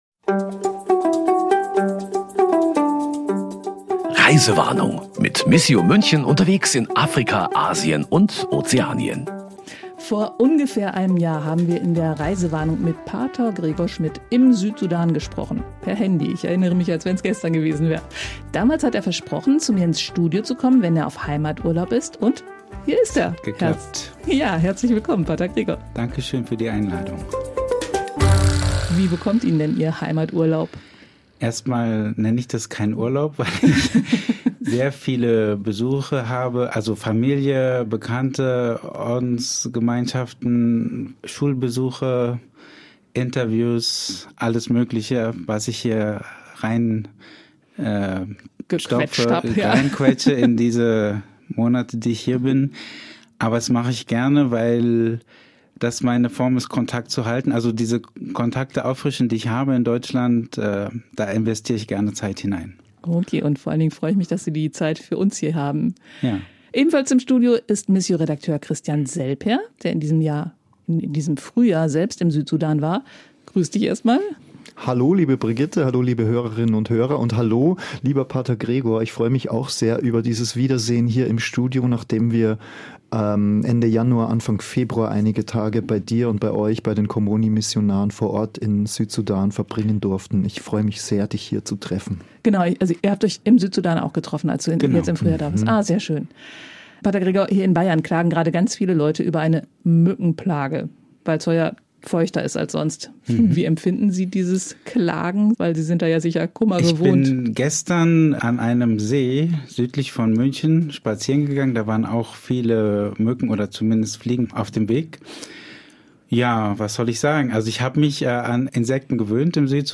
Jetzt ist er auf Heimaturlaub und zu uns ins Podcast-Studio gekommen. Ein weiteres Thema: Ein deutscher Missionar in Afrika im 21.Jahrhundert. Ist das überhaupt noch zeitgemäß?